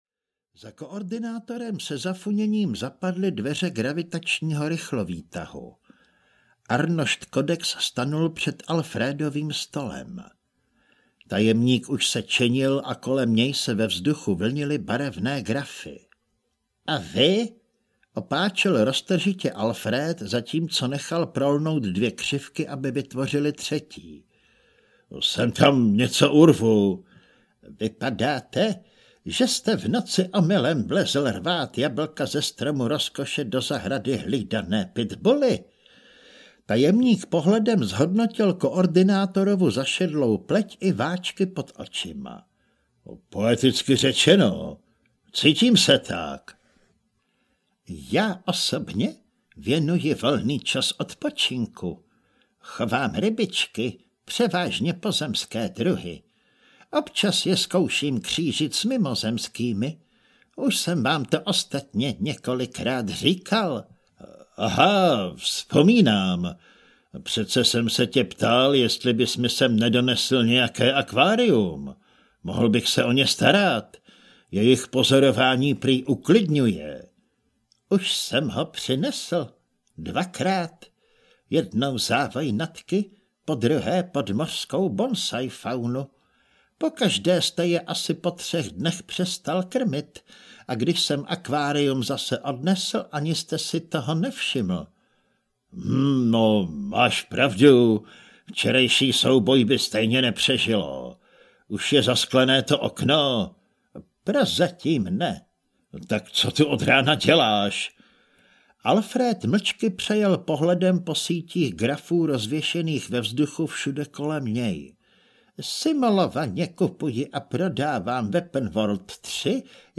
Audio knihaTři kapitáni 2 - Putování
Ukázka z knihy